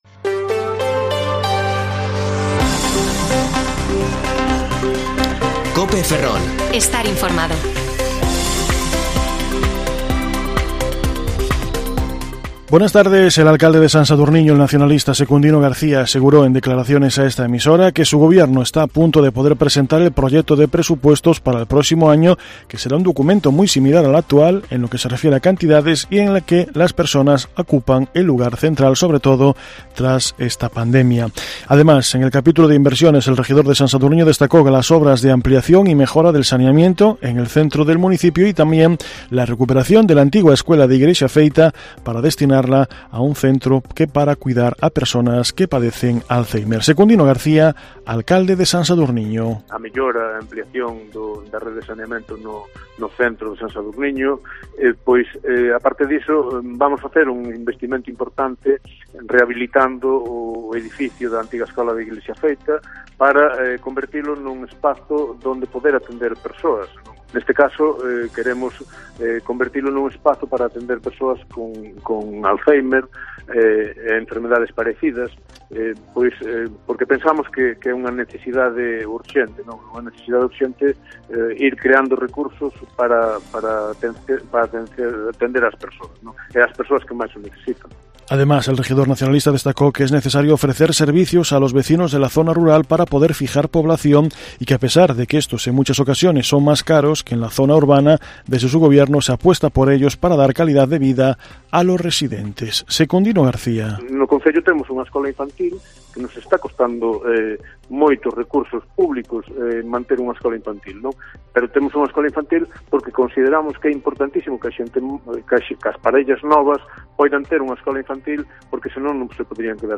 AUDIO: Informativo Mediodía COPE Ferrol 28/12/2021 (De 14,20 a 14,30 horas)